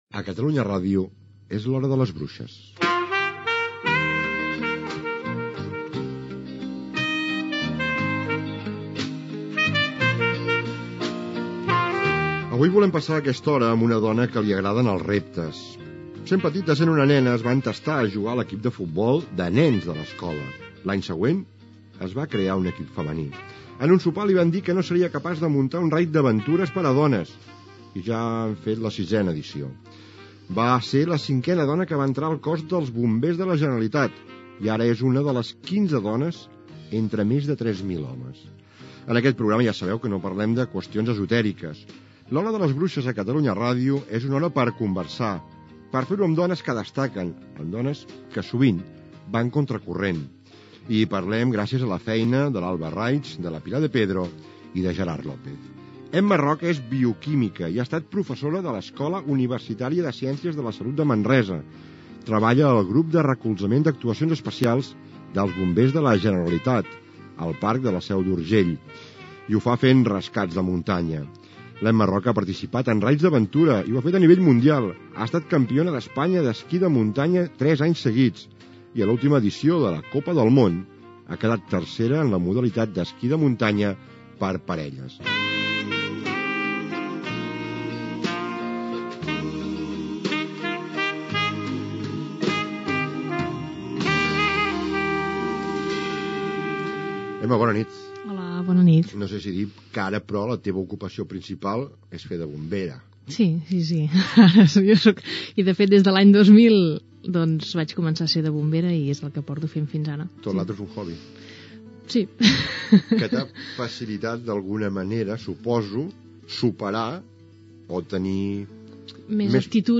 Identificació del programa, presentació i entrevista
Entreteniment